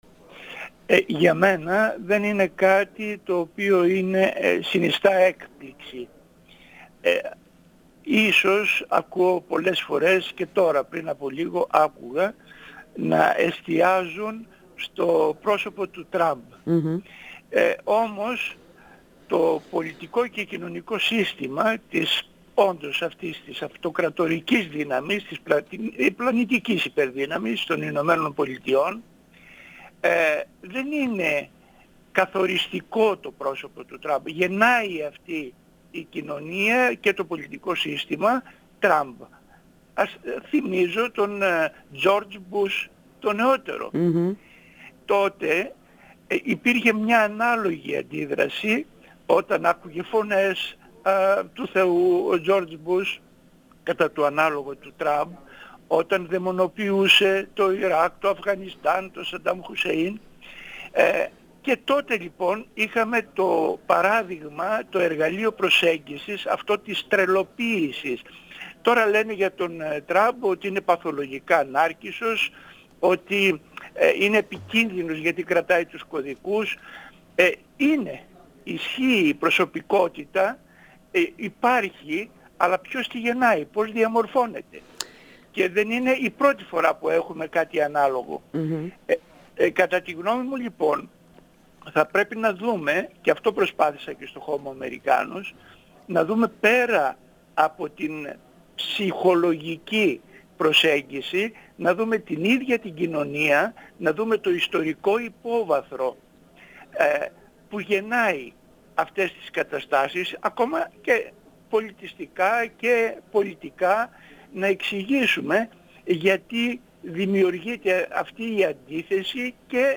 102FM Συνεντεύξεις